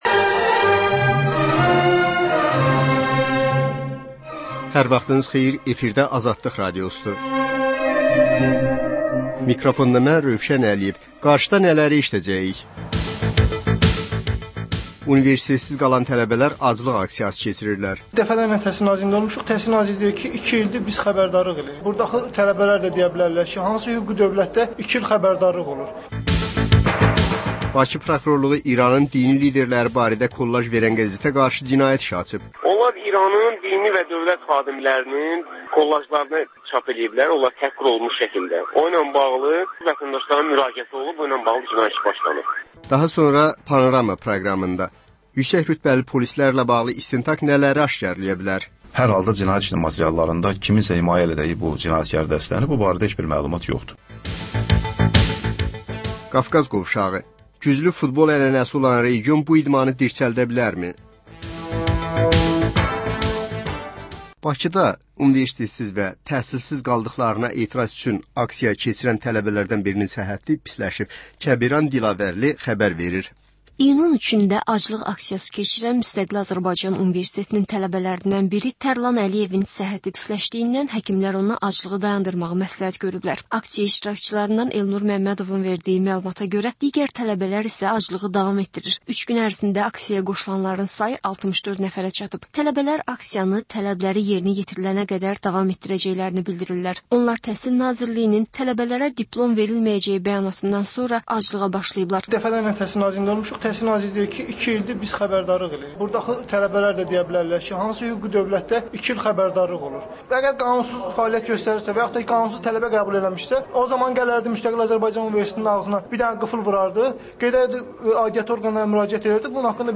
Hadisələr, reportajlar. Panorama: Jurnalistlərlə həftənin xəbər adamı hadisələri müzakirə edir.